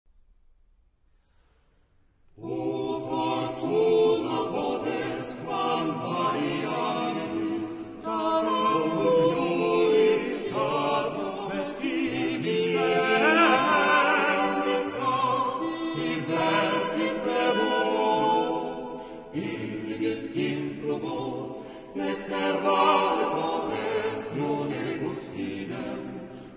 Madrigal Caractère de la pièce : solennel ; vertical
SATB (4 voix mixtes )
Tonalité : do majeur